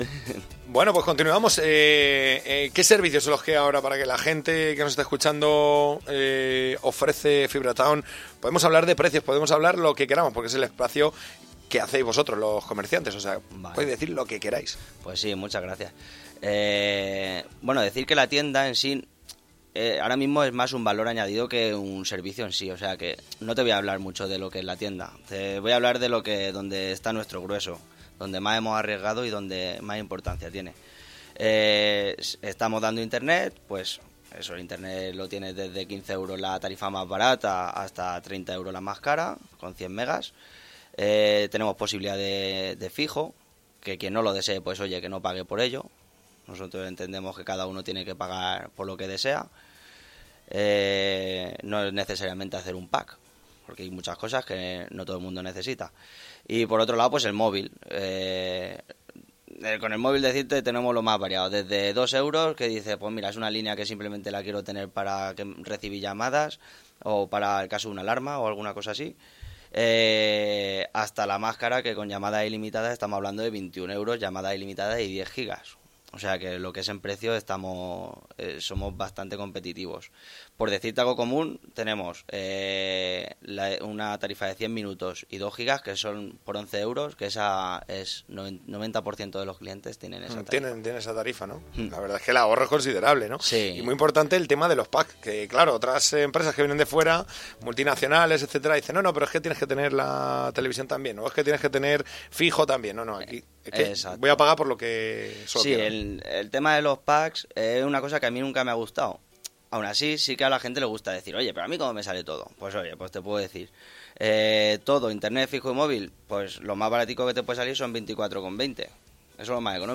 Entrevista en Radio
Os voy a recortar los audios en diferentes puntos que he considerado según fue el transcurso de la entrevista.